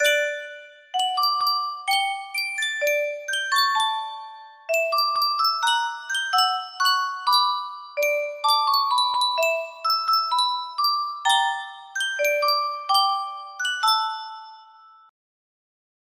Sankyo Music Box - KDR GQS music box melody
Full range 60